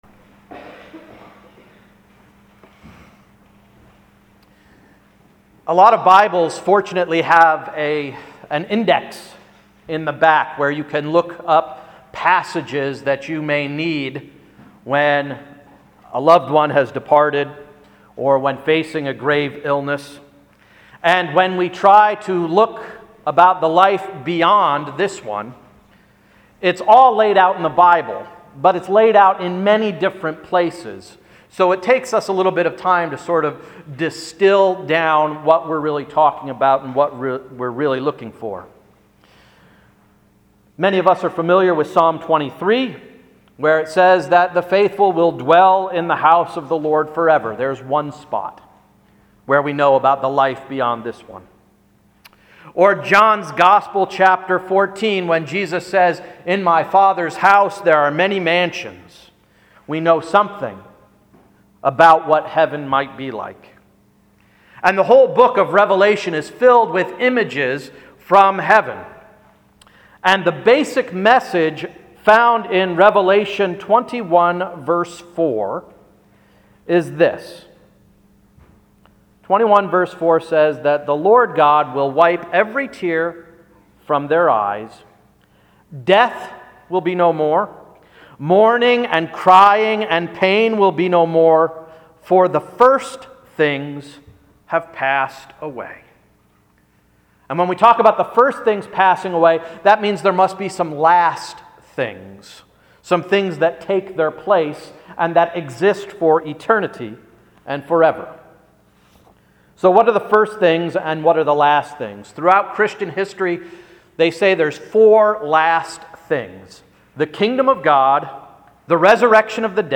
All Saints’ Sermon: “First Things” November 4, 2012